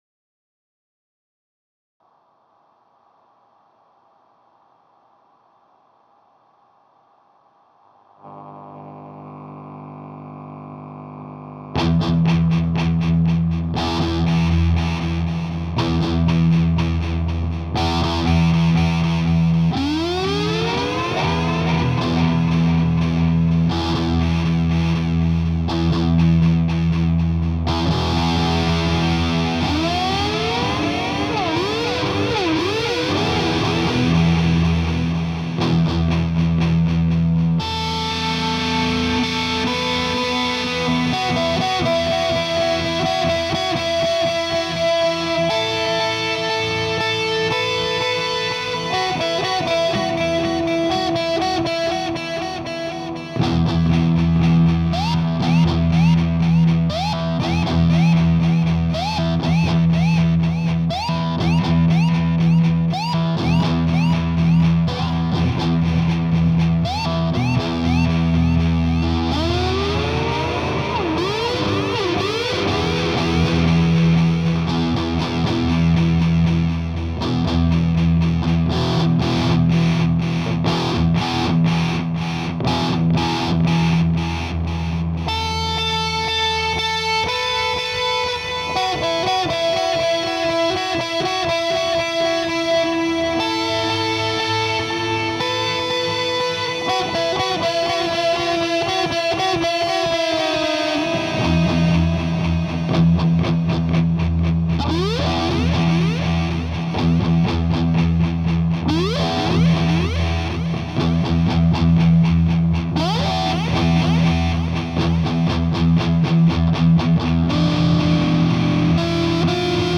ВОТ Записана 1 гитара вот тока какое-то рипение шипение при прослушивании (особенно в начале) dry.gif посоветуйте как его убрать?! если это канечно можно.
Там где соло... уже ничего не поможет.